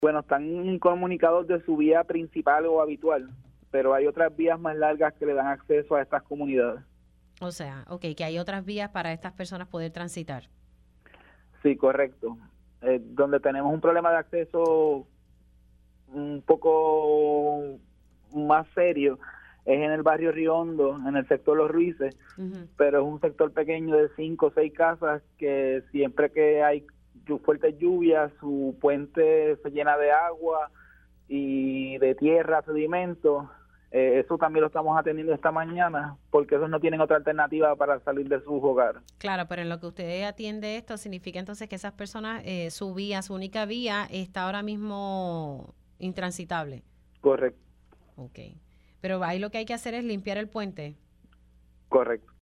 A este mediodía ya debemos haber solucionado todas las situaciones ocasionadas por los eventos de lluvia“, indicó el alcalde de Comerío, Irving Rivera, en Pega’os en la Mañana.